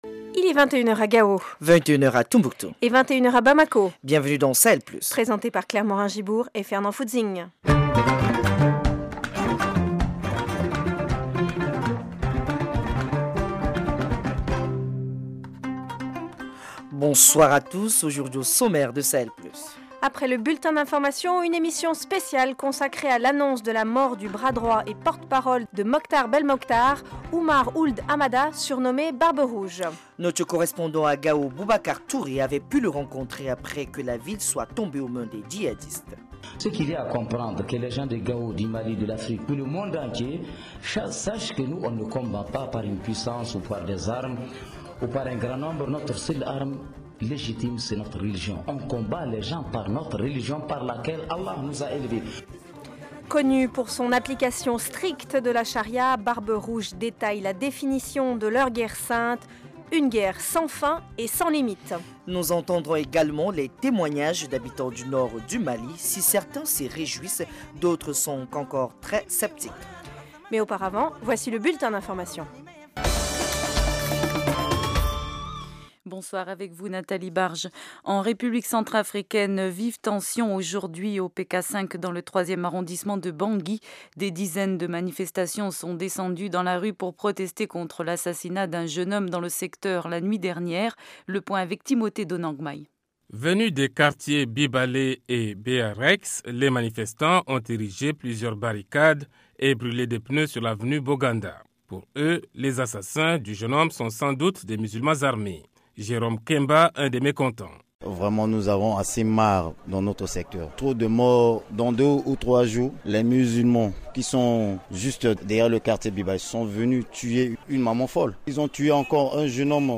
Connu pour son application stricte de la charia, Barbe Rouge détaille la définition de leur guerre sainte : une guerre sans fin et sans limite. Témoignages d’habitants du nord du Mali : si certains se réjouissent de la nouvelle, d’autres sont encore très sceptiques.